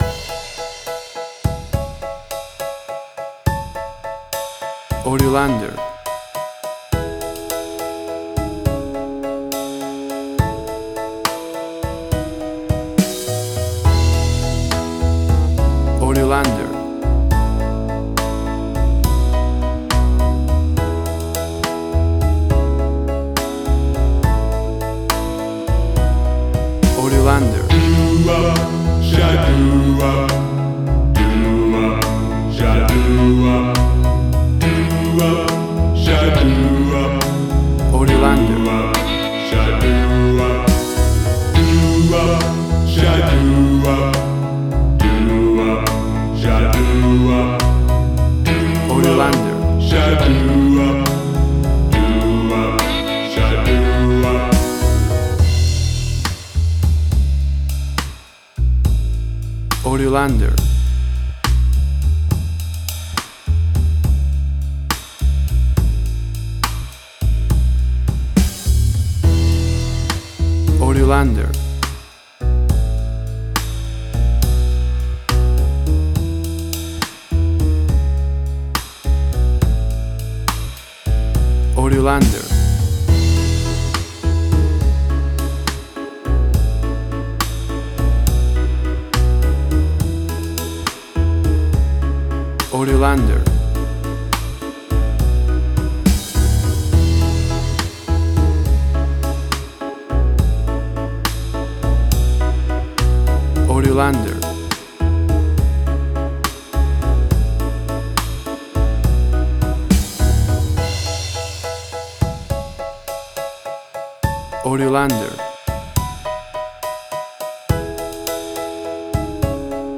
A cool fifties vocal doo wop close harmony group.
WAV Sample Rate: 16-Bit stereo, 44.1 kHz
Tempo (BPM): 69